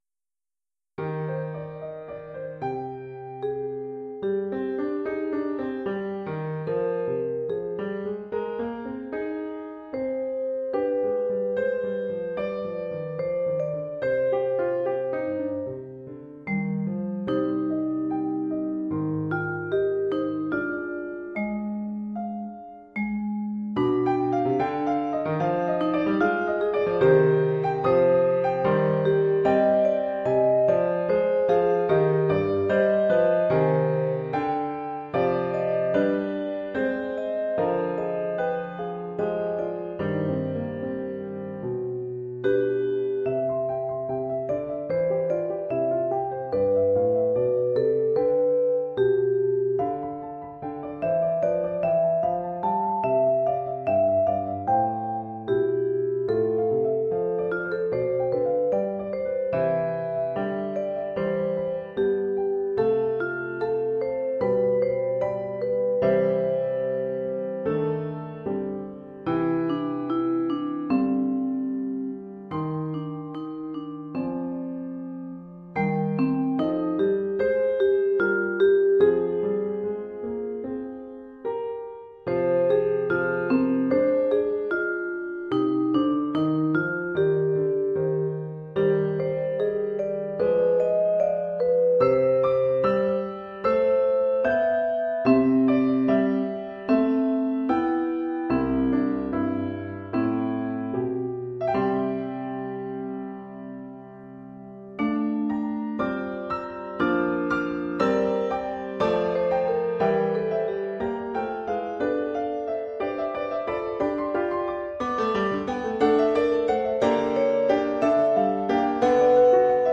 Oeuvre en deux mouvements
pour vibraphone et piano.